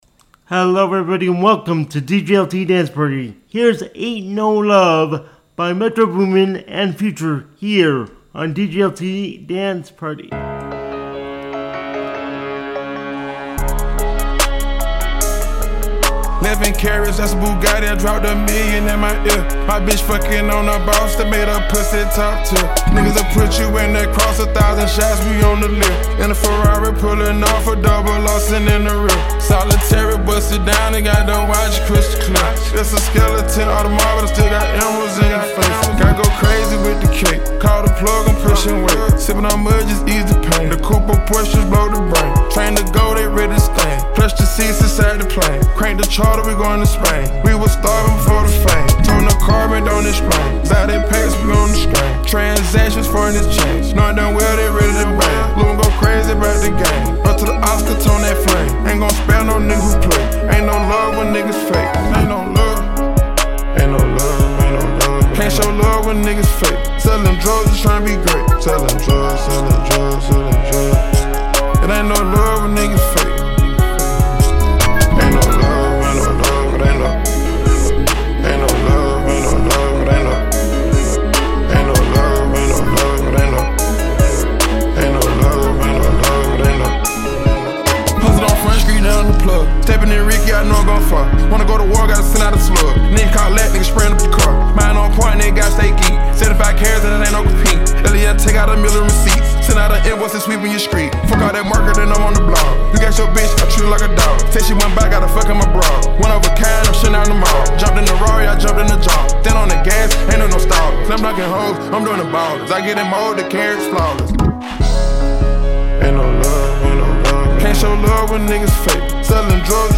If NOT, it is the CLEAN Radio Version.